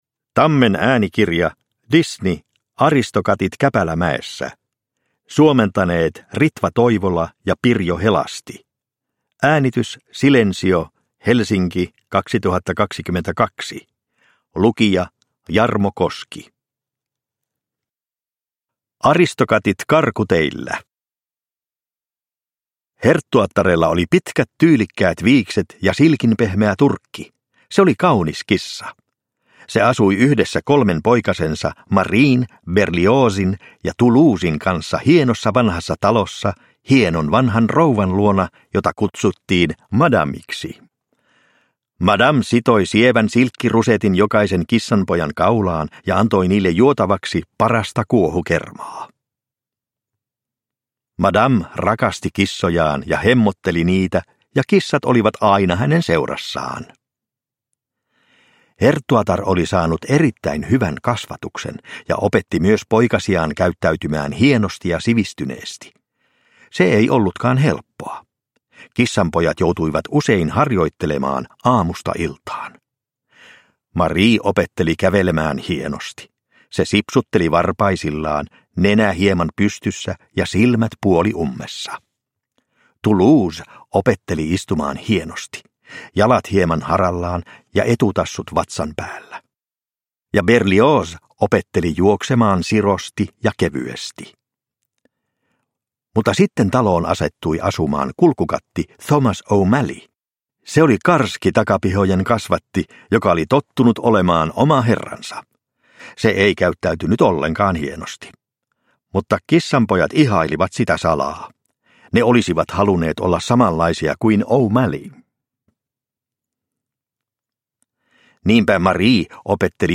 Aristokatit käpälämäessä – Ljudbok – Laddas ner